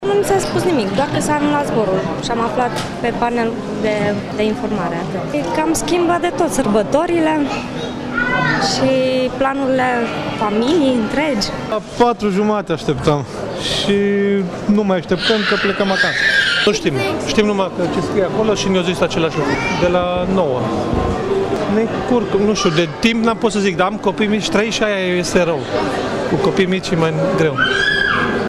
vox-uri-blocati-in-aeroport.mp3